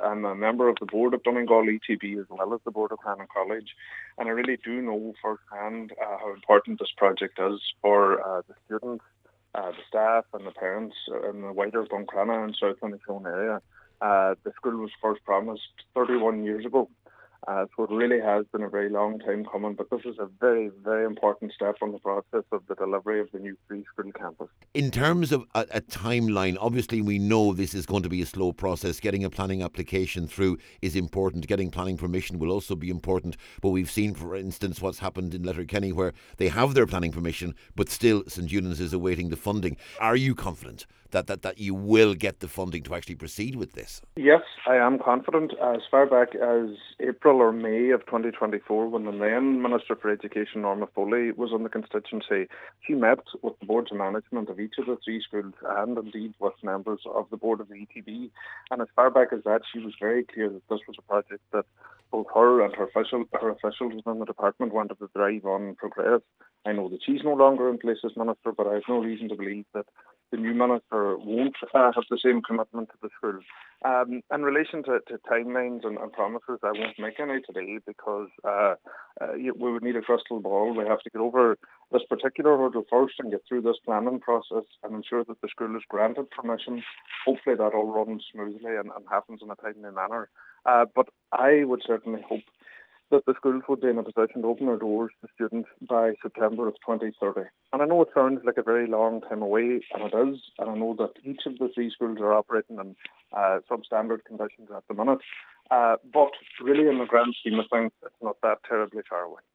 Cllr Fionan Bradley was speaking following confirmation that the formal planning application for the facility has been lodged.